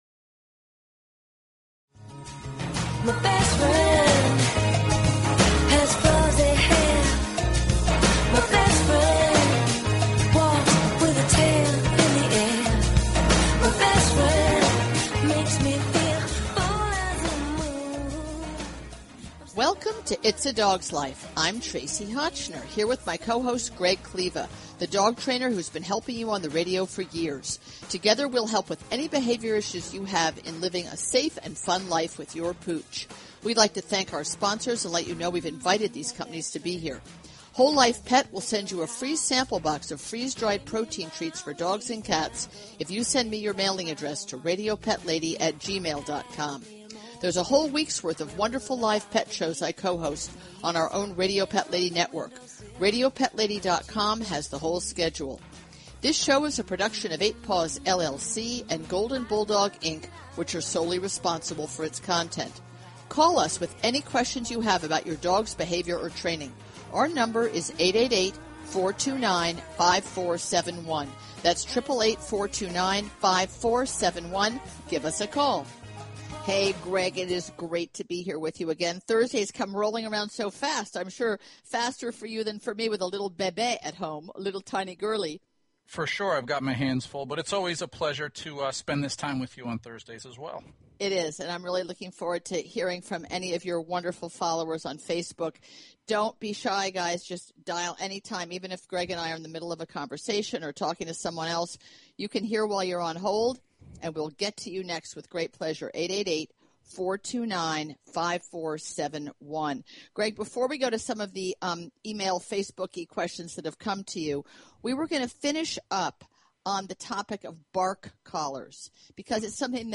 Talk Show Episode, Audio Podcast, Its_A_Dogs_Life and Courtesy of BBS Radio on , show guests , about , categorized as